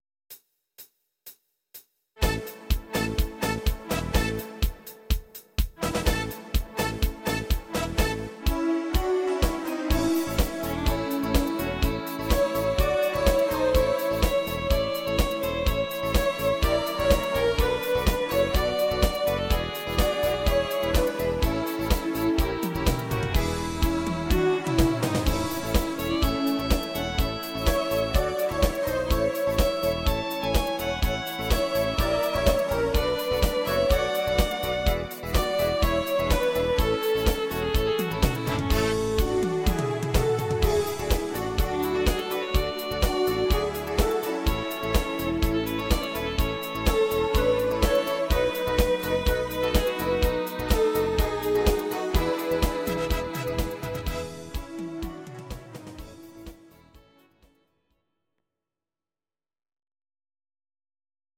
Audio Recordings based on Midi-files
Pop, German, Medleys, 1990s